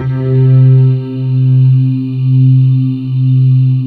Index of /90_sSampleCDs/USB Soundscan vol.28 - Choir Acoustic & Synth [AKAI] 1CD/Partition C/13-MARJOLIE